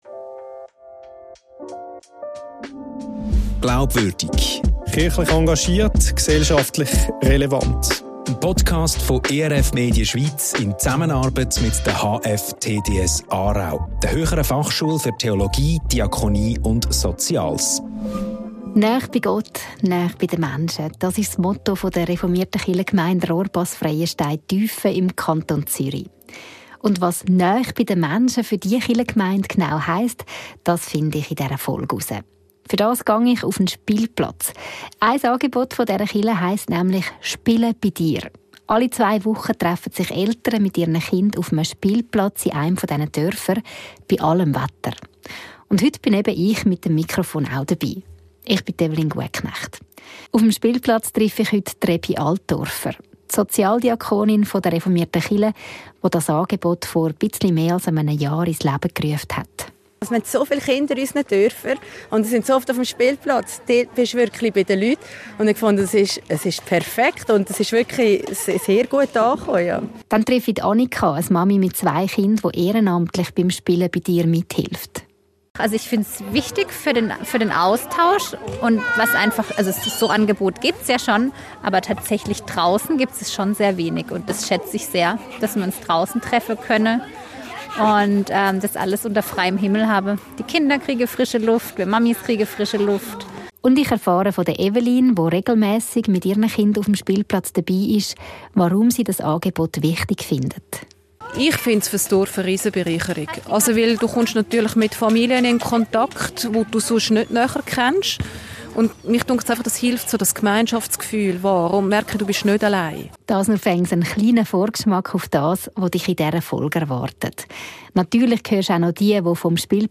An einem Mittwochnachmittag im November auf einem Spielplatz: Rund 20 Kinder wuseln durcheinander – einige klettern, andere bauen mit Holz oder spielen mit Bällen. Manche Mütter machen mit, andere unterhalten sich und knüpfen neue Kontakte.